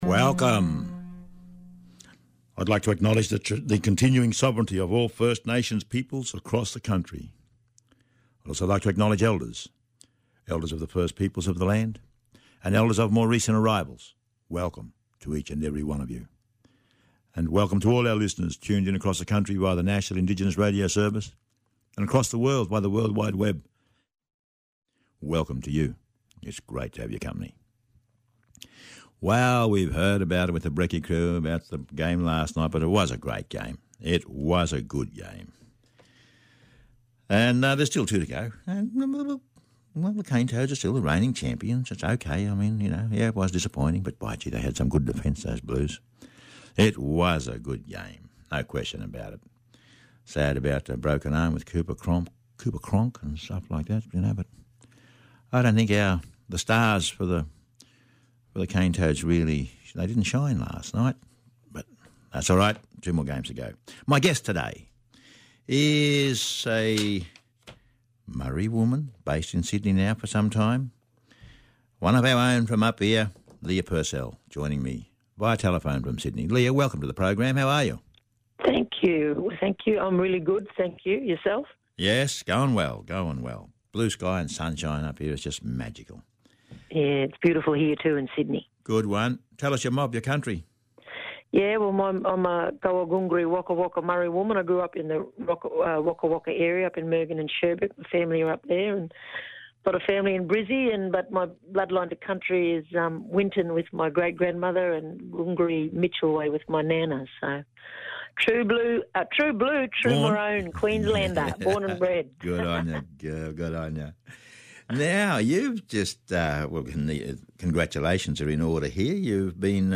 Leah Purcell, actor & writer speaking about winning the Balnaves Foundation Indigenous Playwright’s Award.